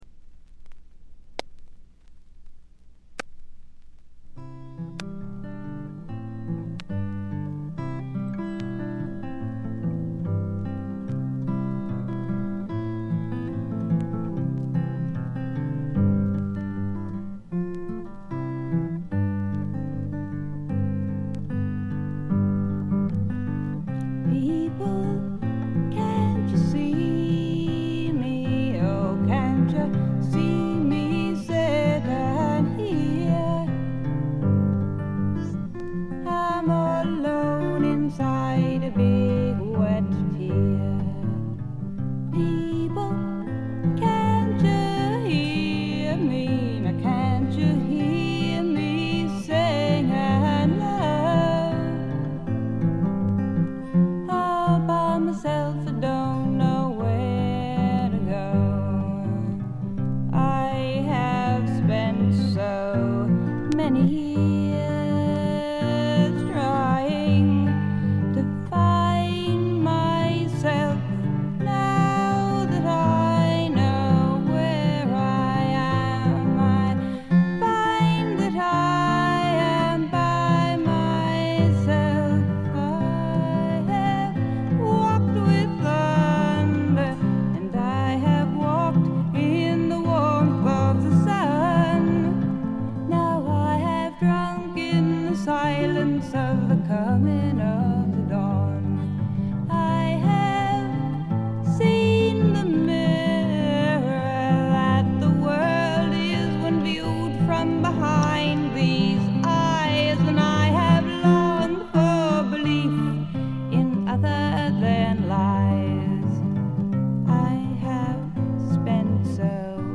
B1冒頭無音部からイントロ頭にかけて周回ノイズ。
自身のギターによる弾き語りで、このアコースティックギターの音が何とも素晴らしく心のひだに沁みわたっていきます。
全体を貫く清澄な空気感と翳りのあるダークな感覚がたまりません。
試聴曲は現品からの取り込み音源です。
Vocals, Guitar